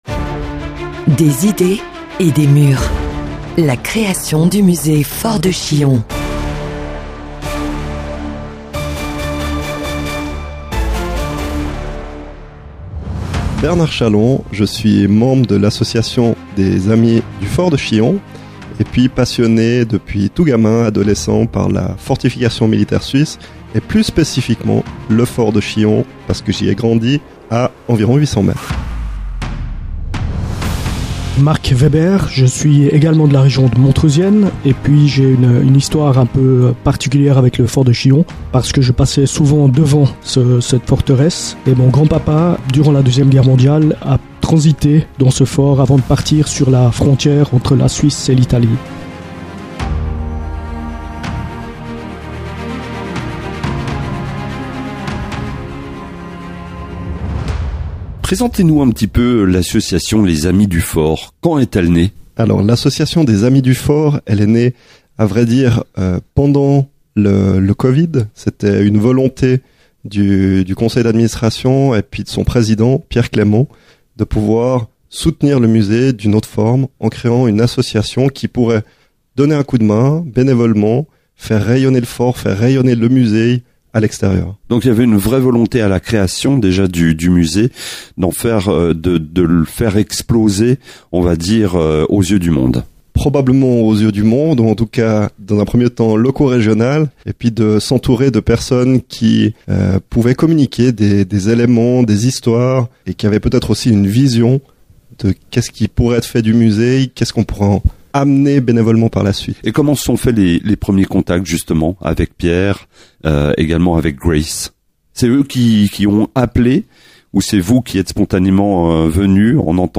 Ces conversations inédites vous plongeront dans les coulisses de l’aventure du Fort, entre histoire, défis et regards passionnés.